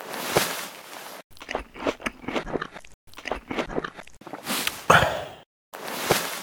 plate_eat.ogg